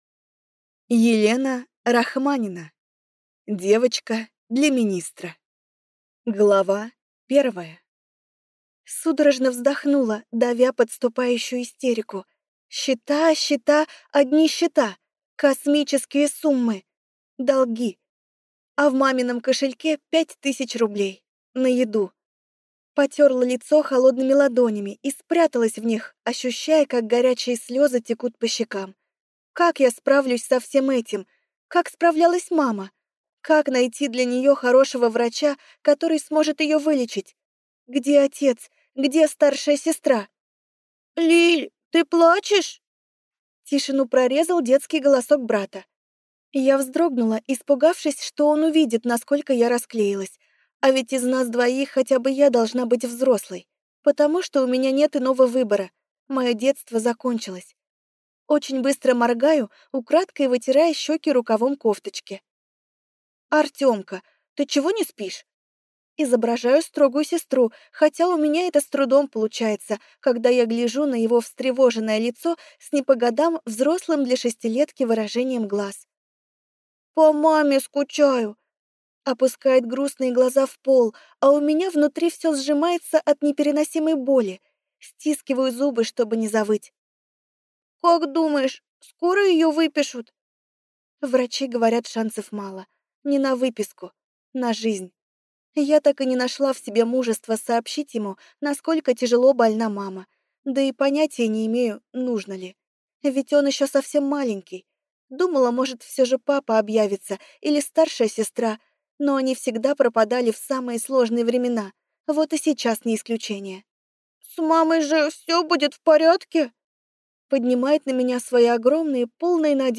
Аудиокнига «Наследница Дальней Пустоши».